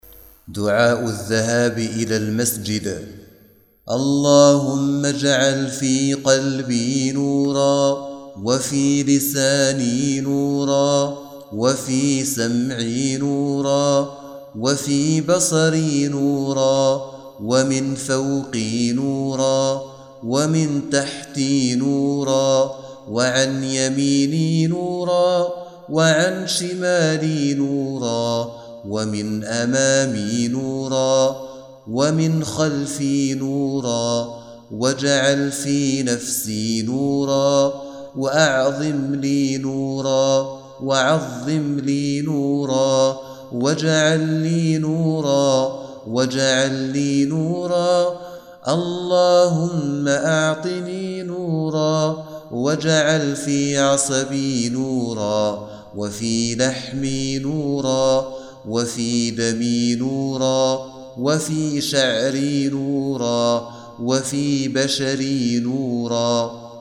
تلاوة لدعاء الذهاب إلى المسجد